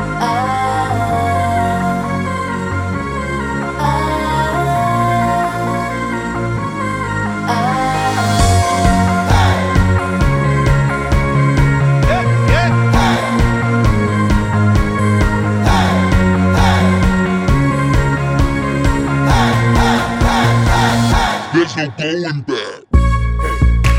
For Solo Female Pop (2000s) 3:04 Buy £1.50